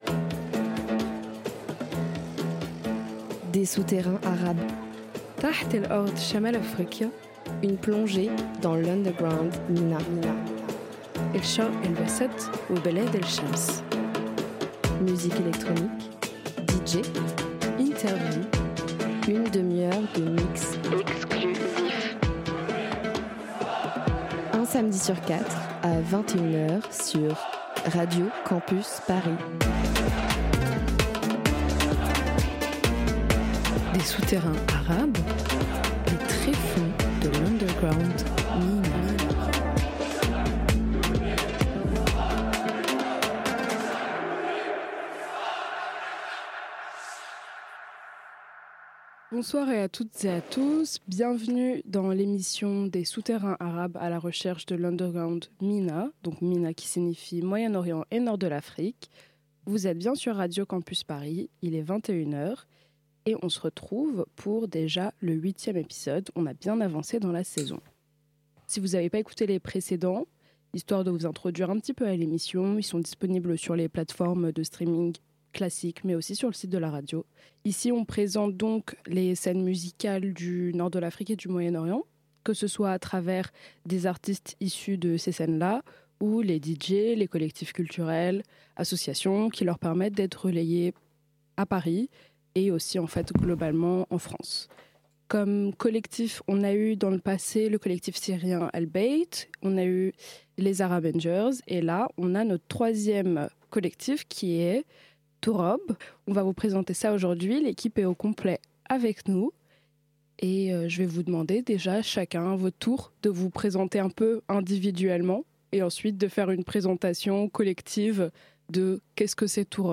Ce soir on a accueilli le collectif culturel événementiel Turrab pour leur première interview radio !
Musicale Électro Autour du globe